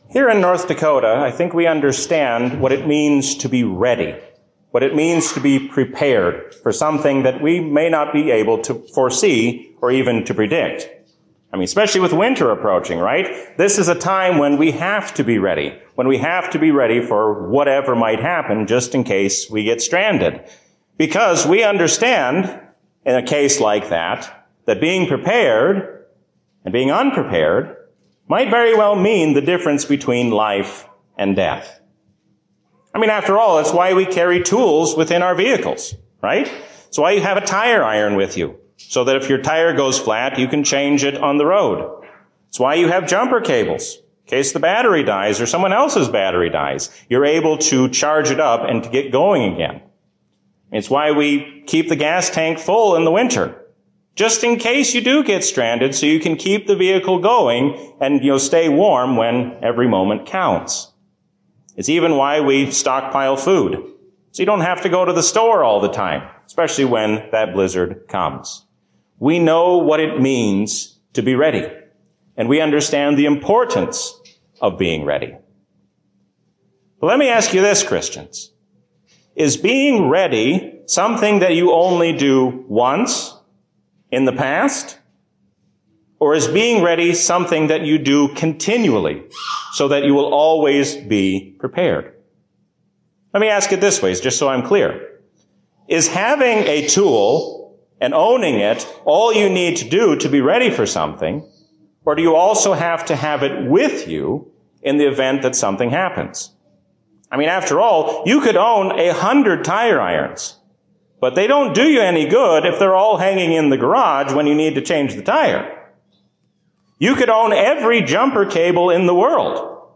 A sermon from the season "Trinity 2024." The New Jerusalem shows us what it will be like to be with God in glory forever.